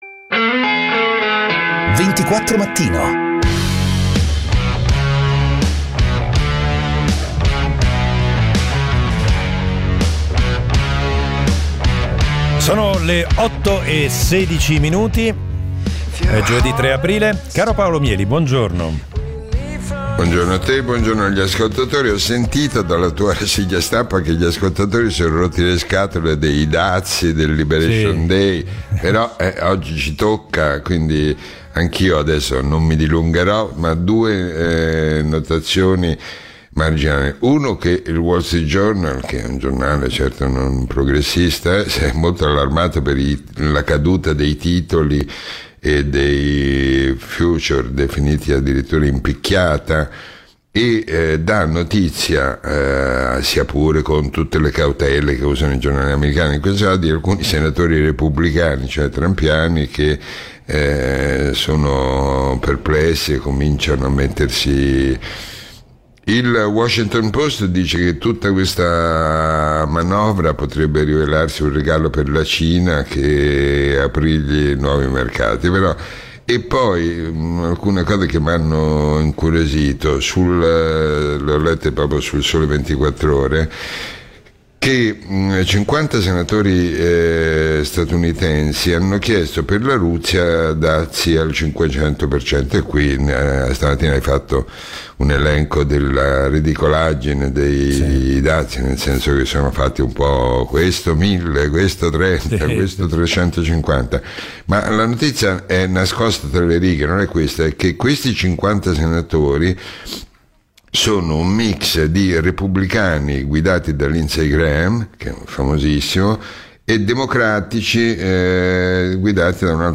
Prima l'imperdibile appuntamento quotidiano con Paolo Mieli per commentare i fatti della giornata. Poi gli ospiti dal mondo della politica, dell'economia, della cronaca, della giustizia: i protagonisti dei fatti o semplicemente chi ha qualcosa di interessante da dire o su cui riflettere, compresi gli ascoltatori.